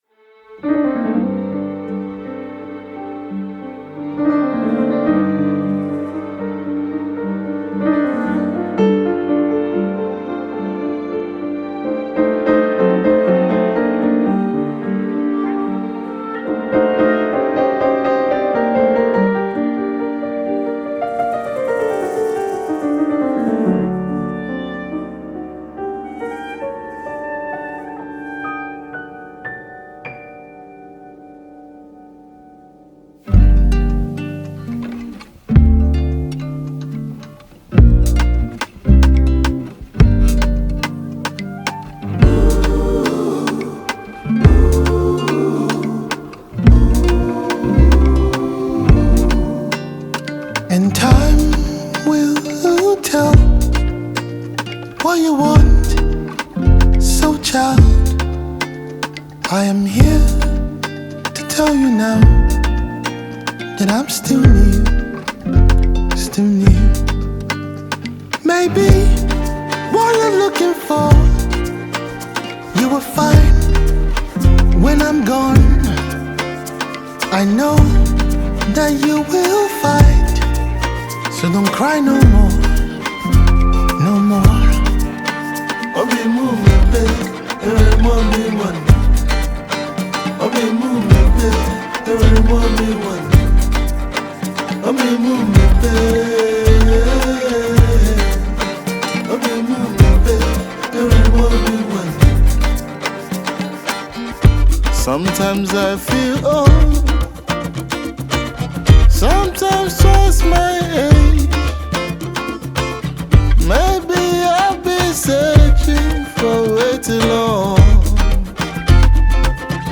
Nigerian award-winning Highlife duo